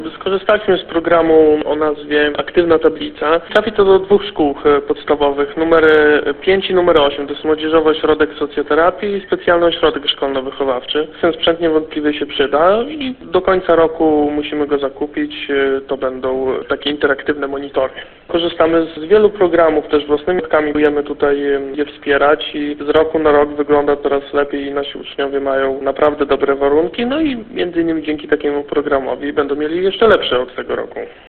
– Dzięki takim dotacjom poprawiają się warunki kształcenia uczniów – mówi wicestarosta powiatu giżyckiego, Mateusz Sieroński.